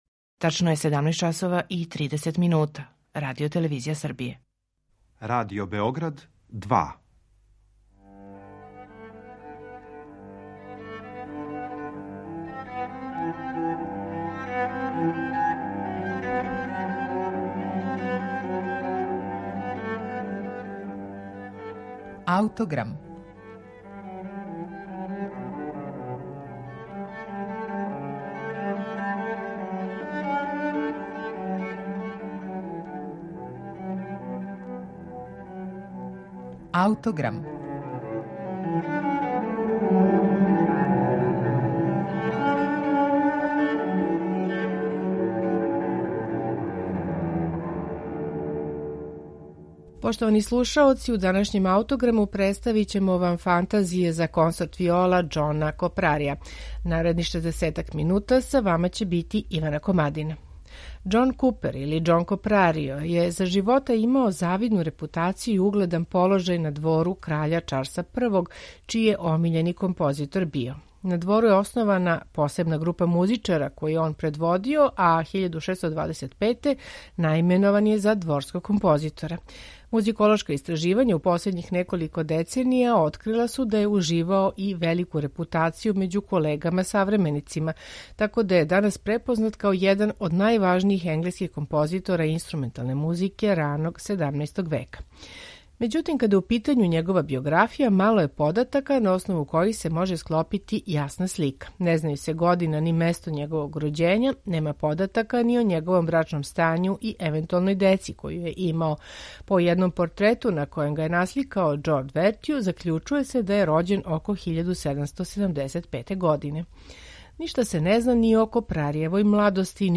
Копрарио: Музика за консорт виола